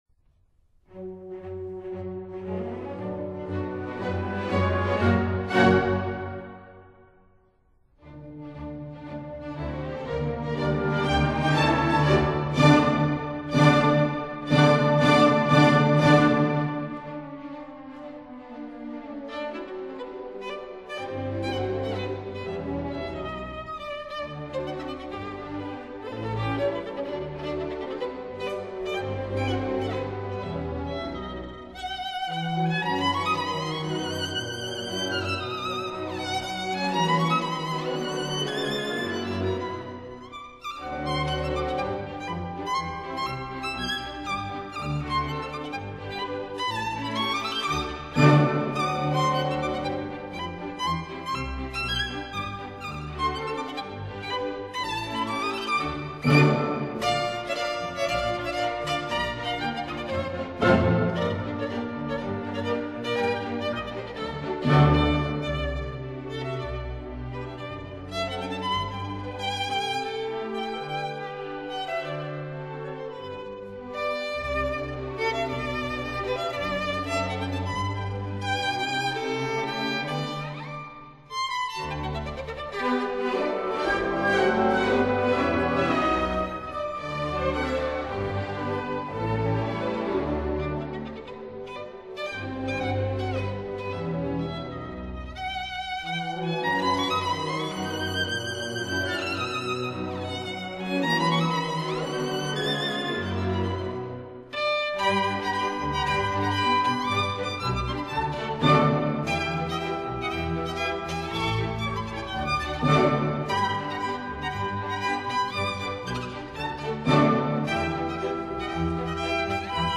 Allegro moderato